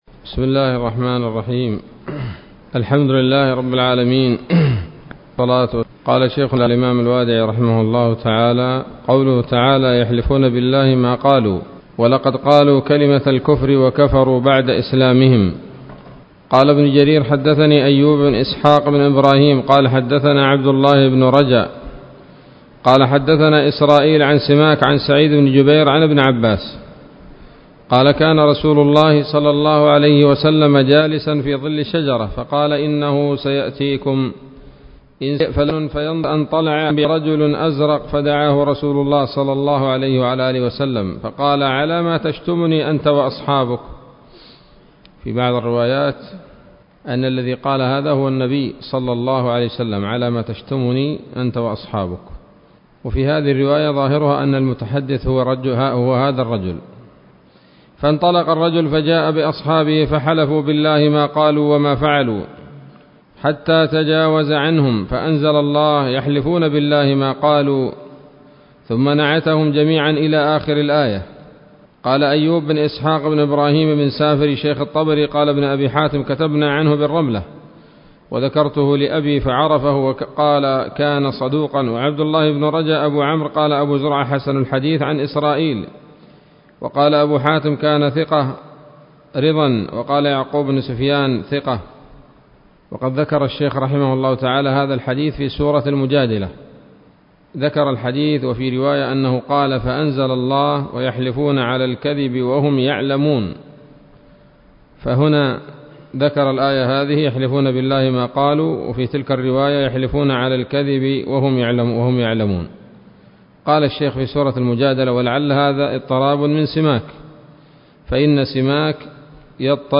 الدرس الثالث والأربعون من الصحيح المسند من أسباب النزول